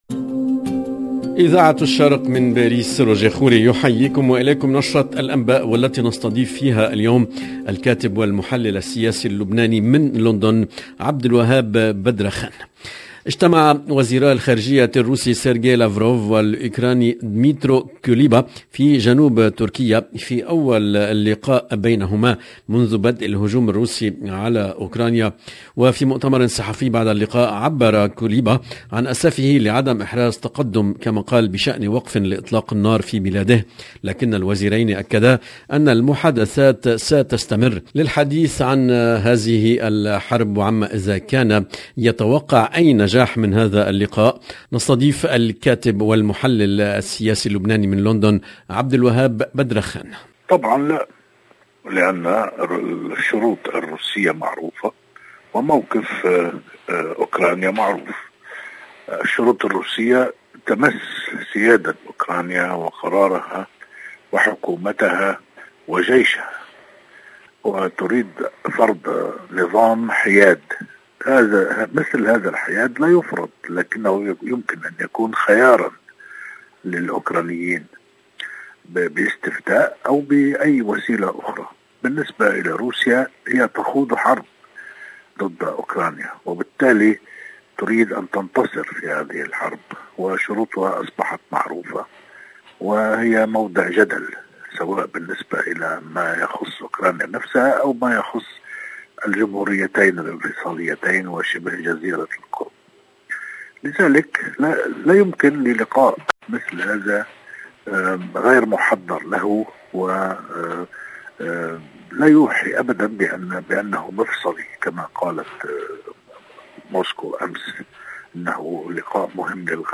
EDITION DU JOURNAL DU SOIR EN LANGUE ARABE DU 10/3/2022